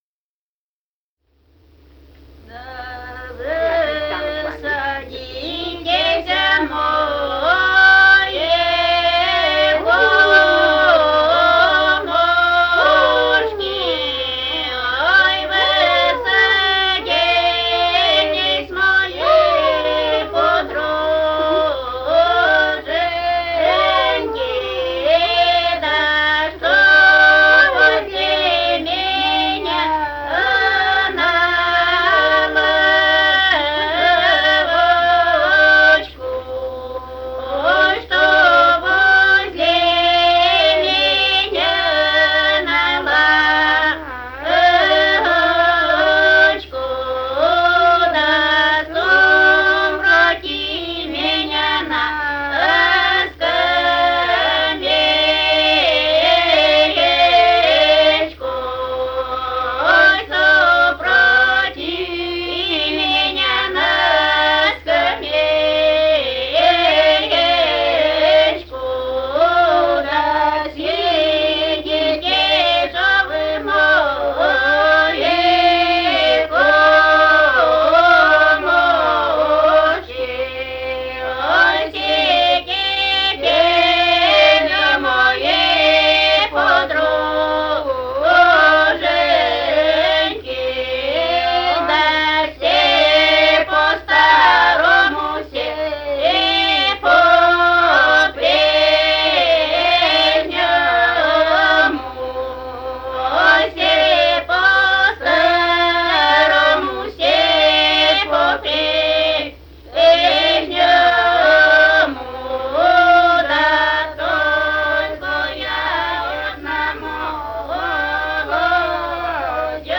полевые материалы
Пермский край, д. Монастырка Осинского района, 1968 г. И1074-27